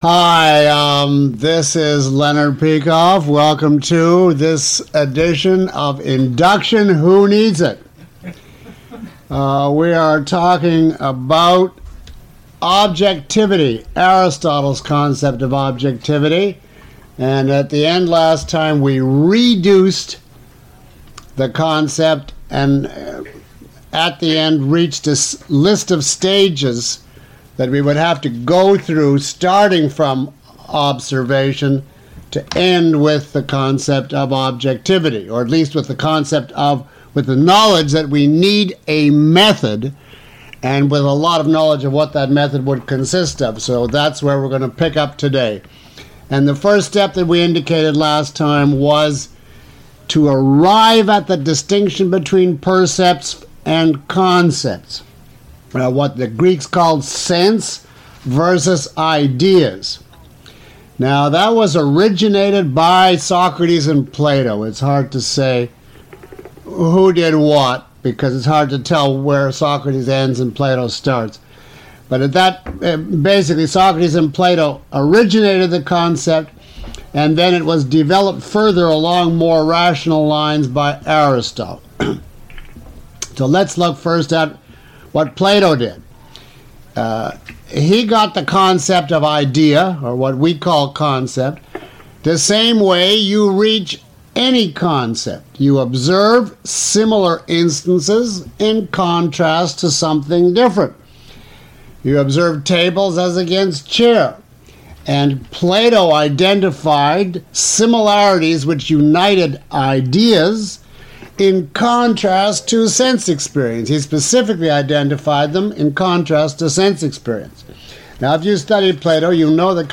Lecture (MP3) Full Course (ZIP) Lecture Five Course Home Lecture Seven Questions about this audio?